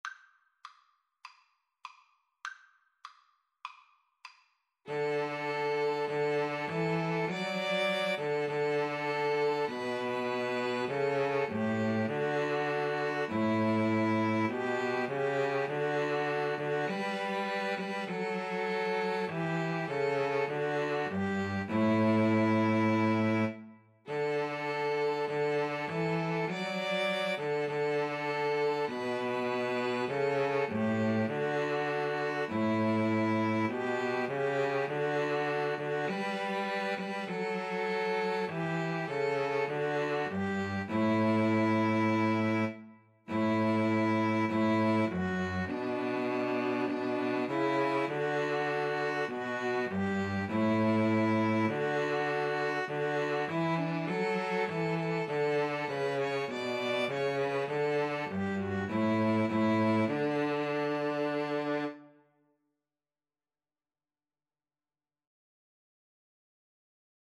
Maestoso
Piano Trio  (View more Easy Piano Trio Music)